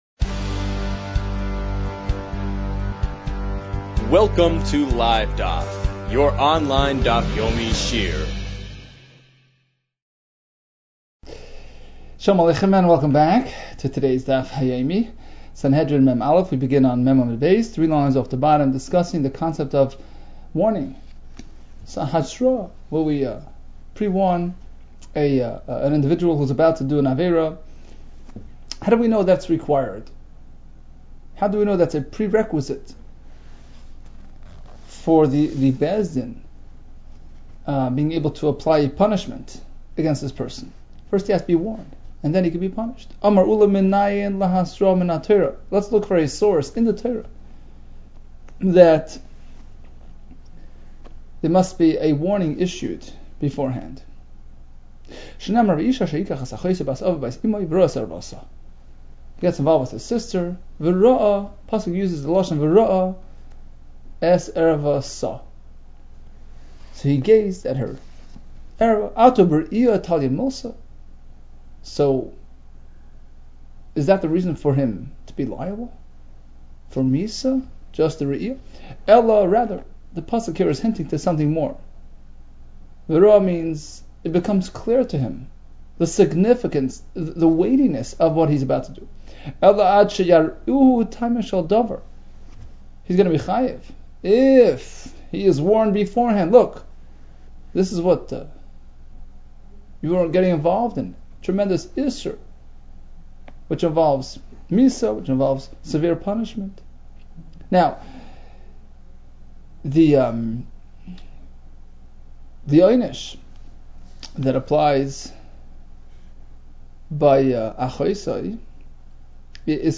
Sanhedrin 40 - סנהדרין מ | Daf Yomi Online Shiur | Livedaf